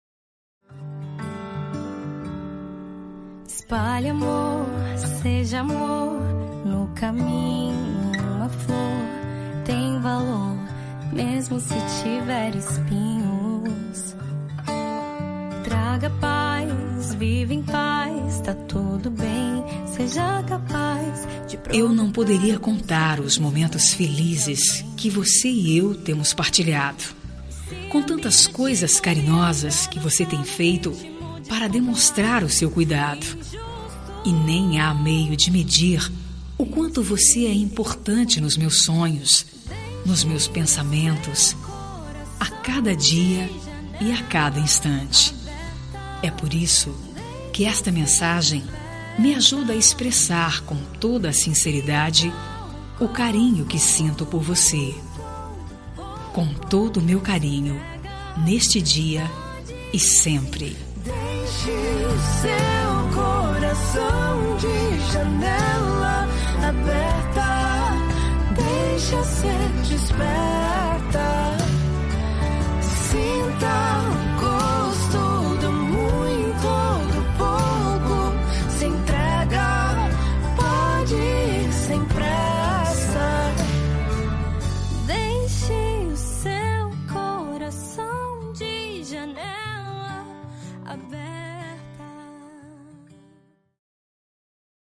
Telemensagem Romântica – Voz Feminina – Cód: 6450